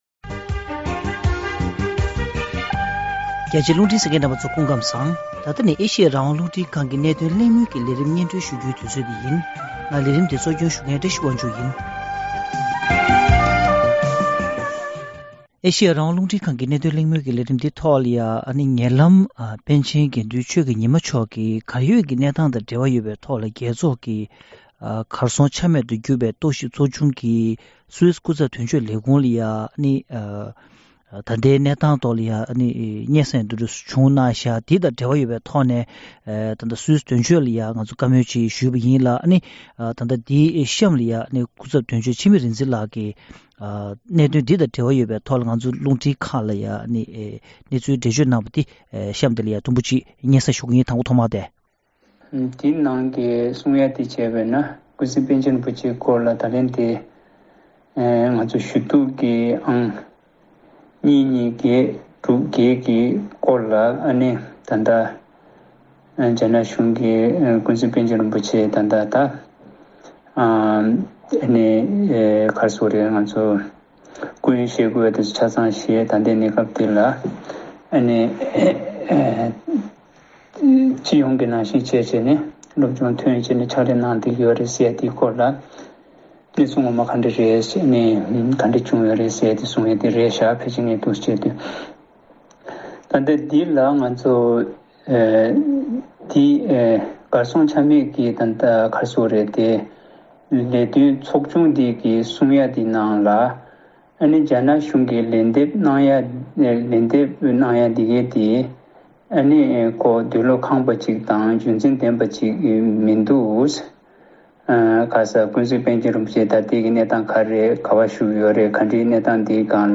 བཀའ་དྲི་ཞུས་པ་ཞིག་སྙན་སྒྲོན་ཞུ་རྒྱུ་ཡོད།